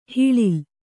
♪ hiḷil